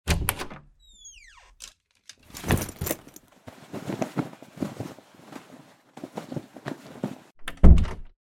wardrobe_1.ogg